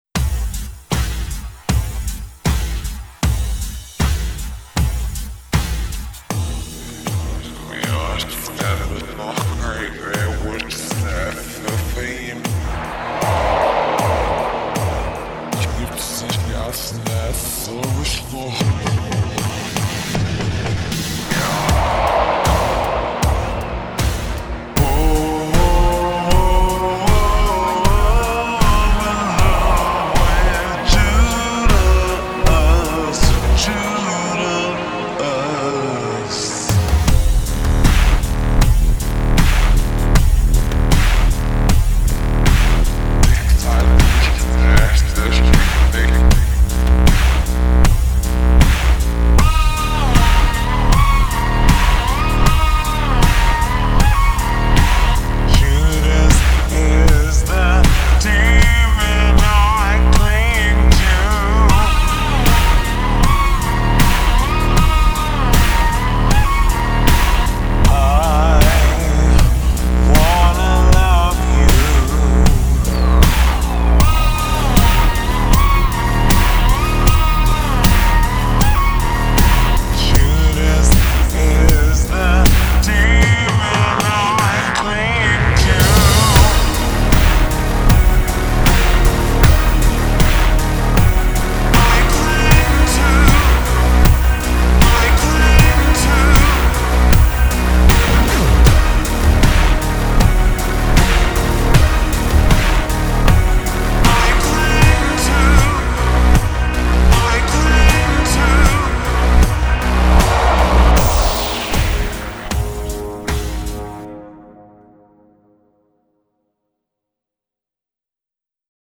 BPM78